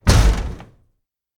328d67128d Divergent / mods / Soundscape Overhaul / gamedata / sounds / ambient / soundscape / underground / under_24.ogg 41 KiB (Stored with Git LFS) Raw History